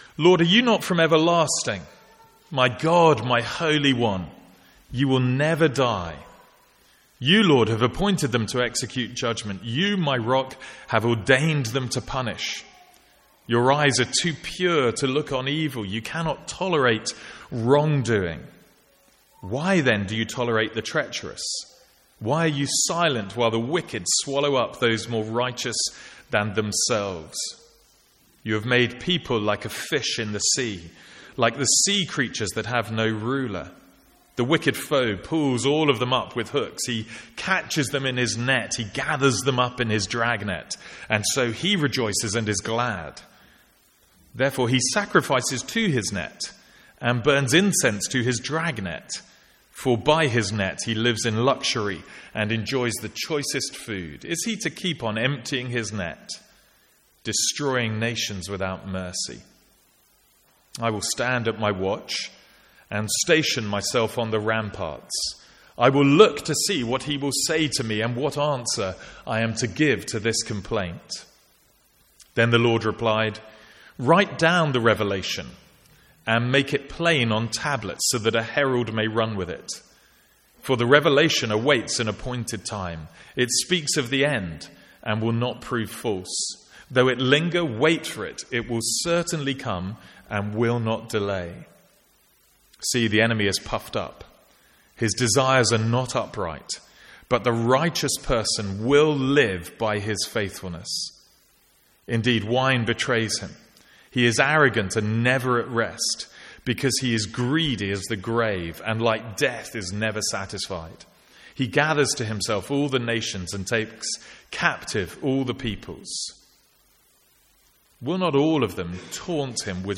Sermons | St Andrews Free Church
From the Sunday morning series in Habakkuk.